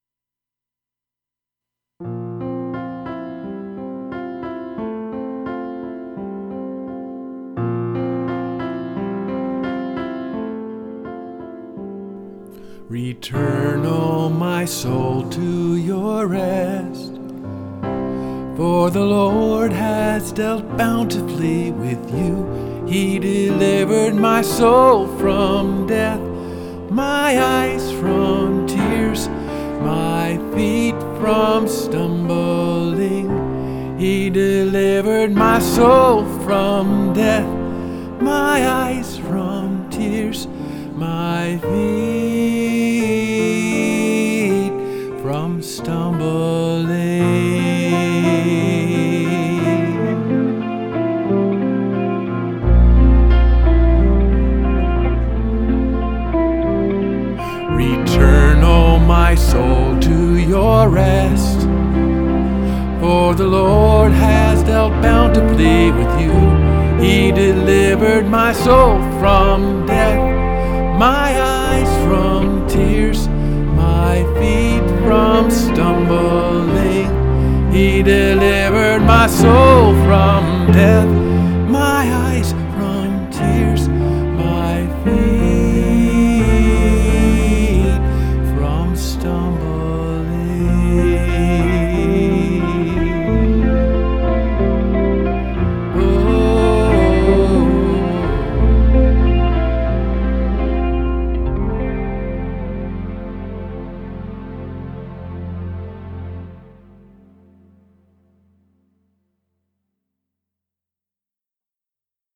2025 studio version